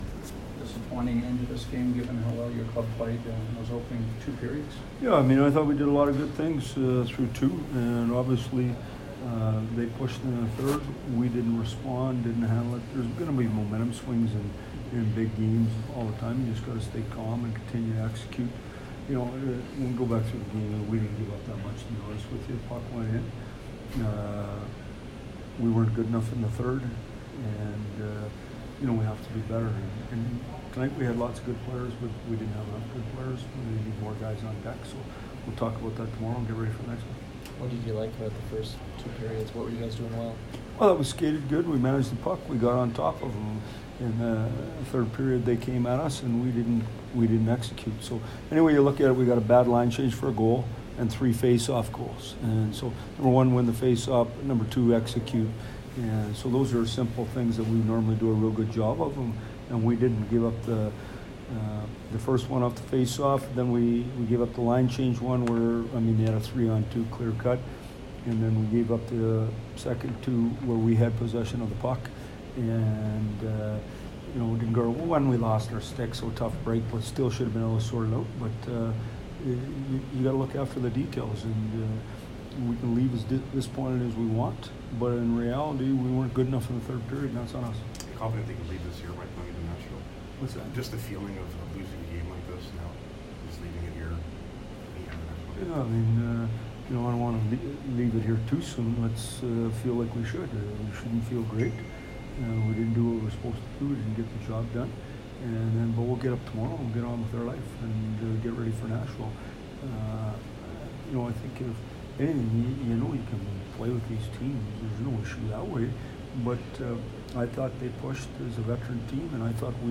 Maple Leafs head coach Mike Babcock post-game 3/20